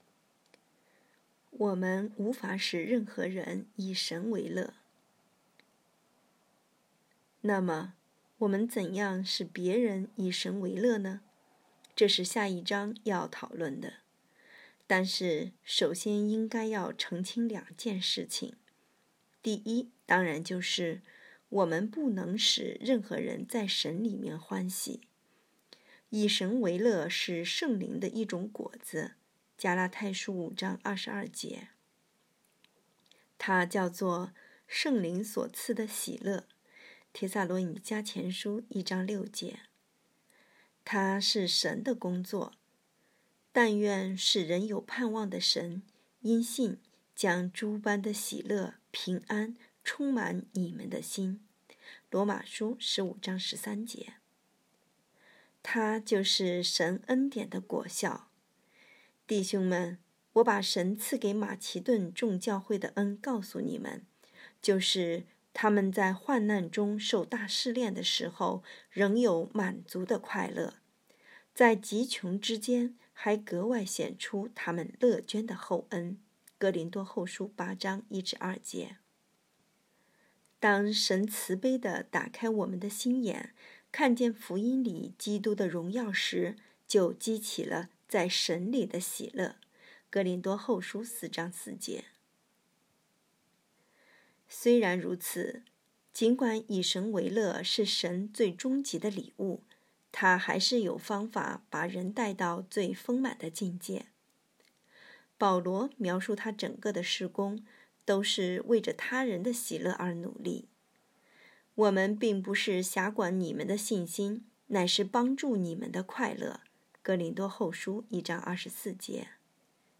2024年4月16日 “伴你读书”，正在为您朗读：《活出热情》 欢迎点击下方音频聆听朗读内容 https